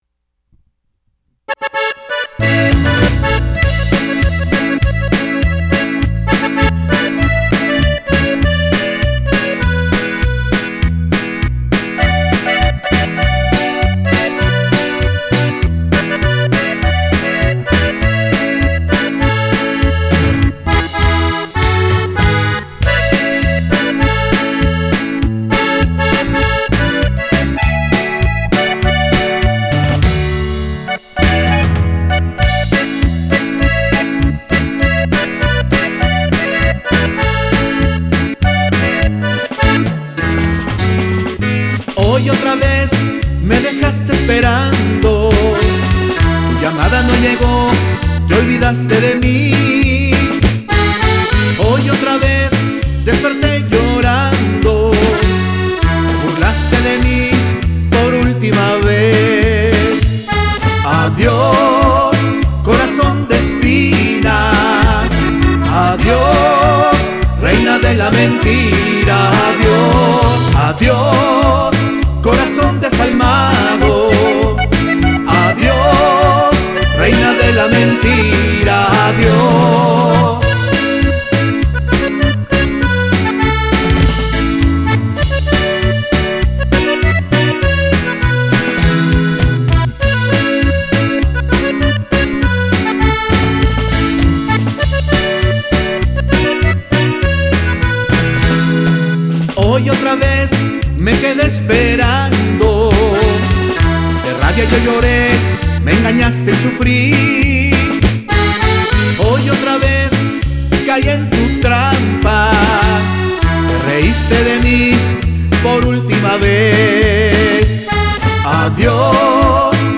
norteño